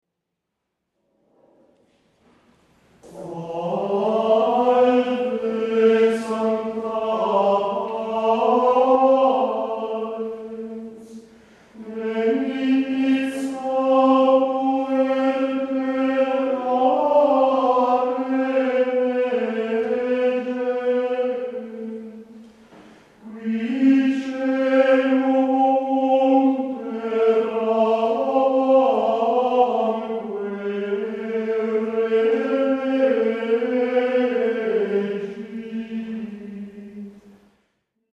canto gregoriano
Salve Sancta parens introito